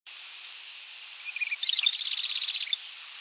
Marsh Wren
River Drive South, Sayerville, near Raritan River, 5/5/03 (13kb)
wren_marsh_745.wav